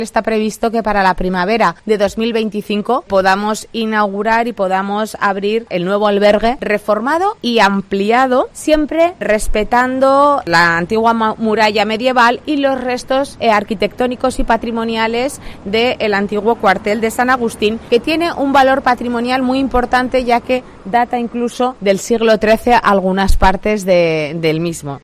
La alcaldesa explica la reforma del Albergue Municipal